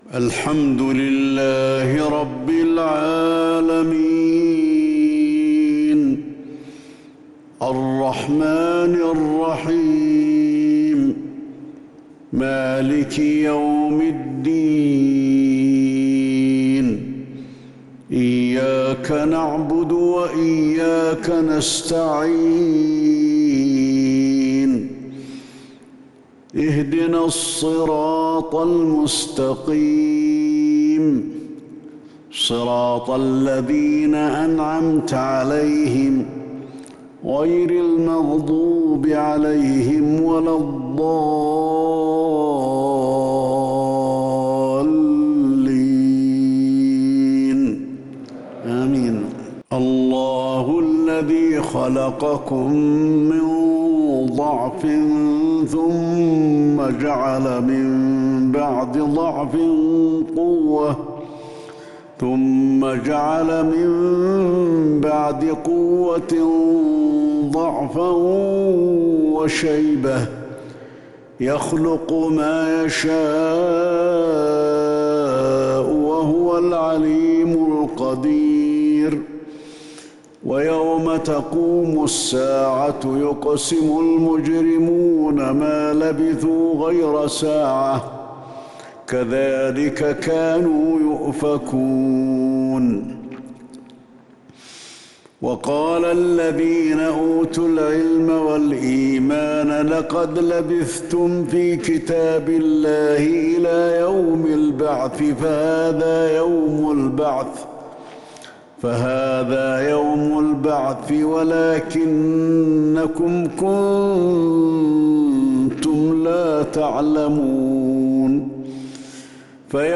صلاة العشاء للشيخ علي الحذيفي 13 شوال 1442 هـ
تِلَاوَات الْحَرَمَيْن .